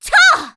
cleric_f_voc_attack02_b.wav